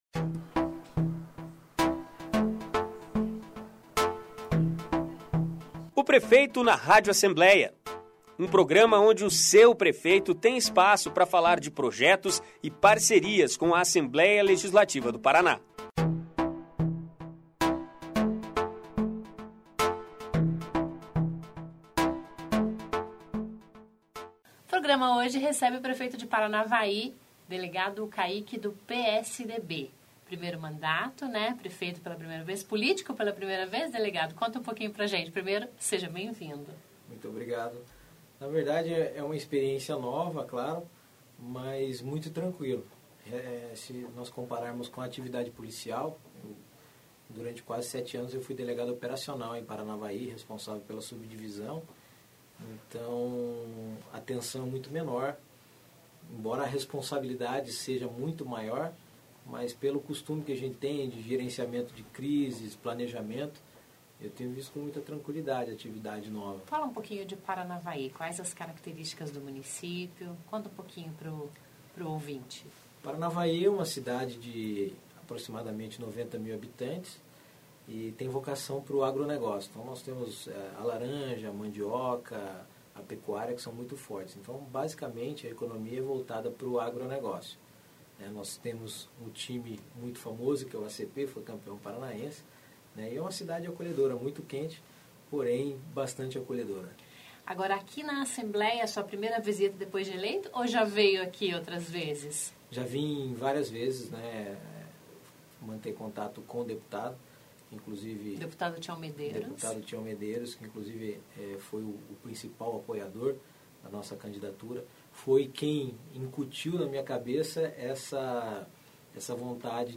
Ouça a entrevista com Delegado Caíque (PSDB), de Paranavaí, que nunca nem sonhou em ser político, mas foi convencido por amigos e pelo deputado Tião Mederios (PTB) para se lançar candidato.
Uma delas é que ele é amante de rock pesado. Então, ouça na íntegra esse delicioso bate papo com o  convidado desta semana do "Prefeito na Rádio Alep".